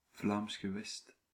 The Flemish Region (Dutch: Vlaams Gewest, pronounced [ˌvlaːms xəˈʋɛst]